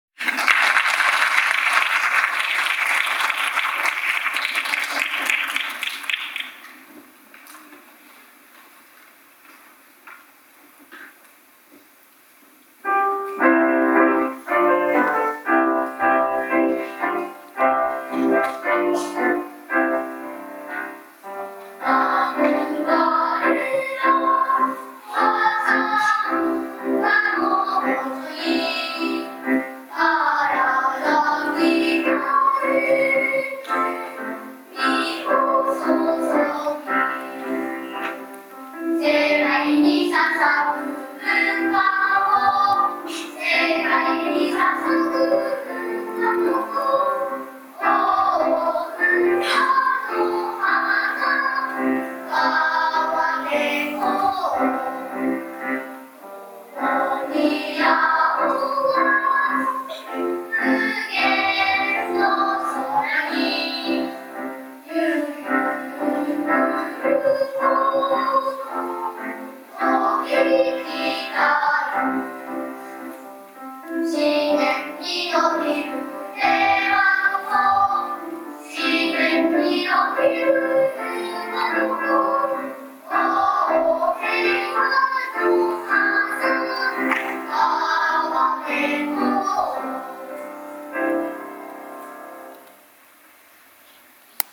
お知らせ 天使の歌声 響く　～勿来･遠野･田人方部音楽祭～
伴奏に一人回ると１０名の歌声になります。
1曲目は「校歌」です。ていねいな伴奏にのって、美しい高音を響かせました。
ICレコーダーでの録音ですが、お聞きください。